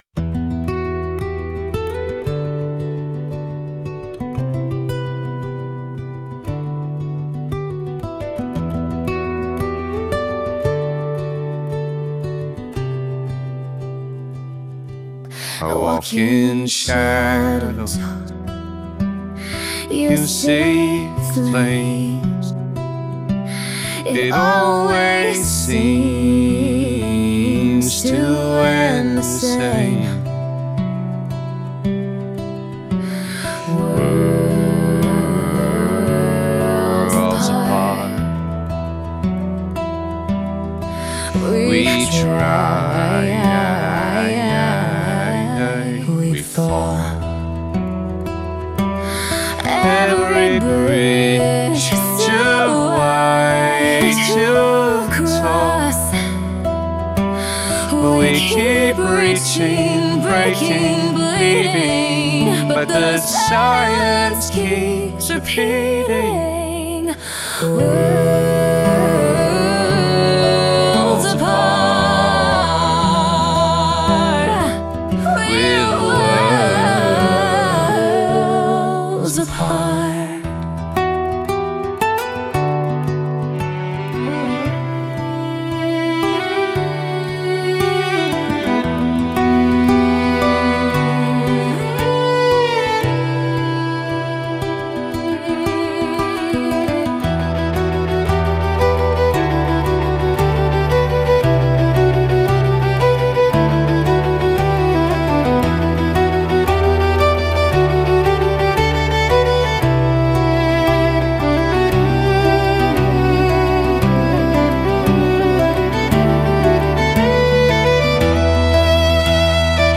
Worlds-Apart(Duet).mp3